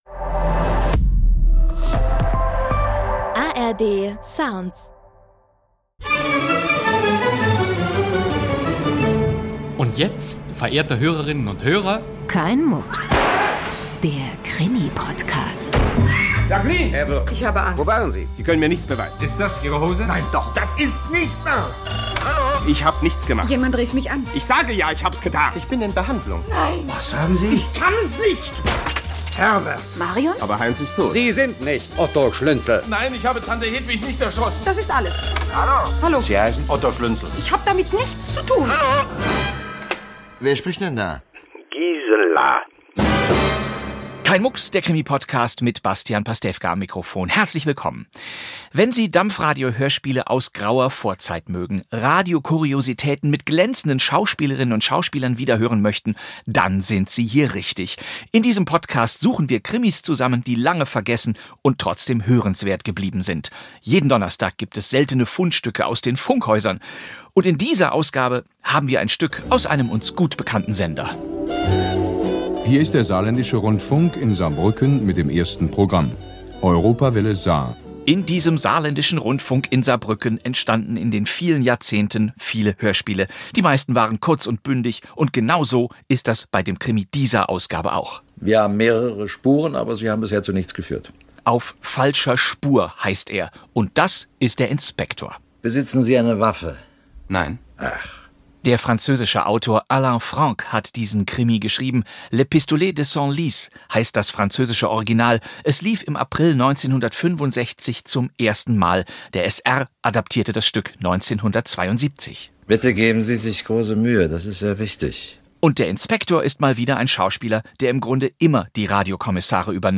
Krimi-Podcast mit Bastian Pastewka ~ Kein Mucks!